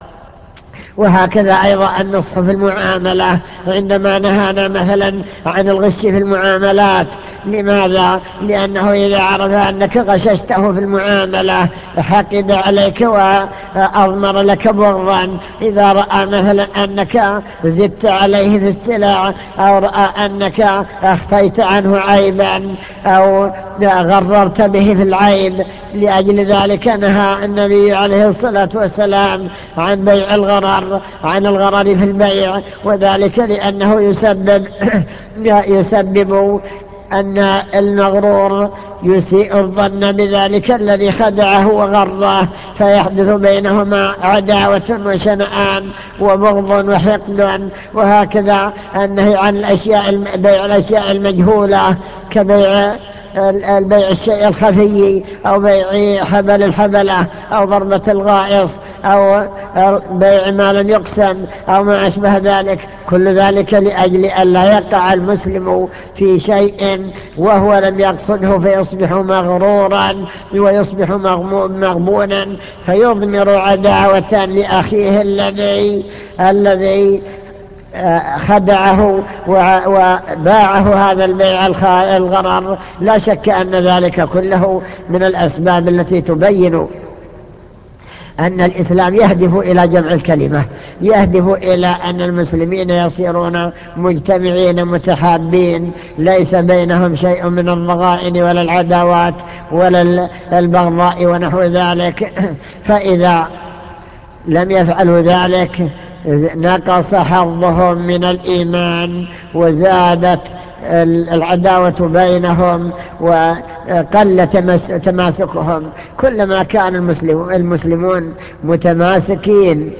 المكتبة الصوتية  تسجيلات - محاضرات ودروس  درس الآداب والأخلاق الشرعية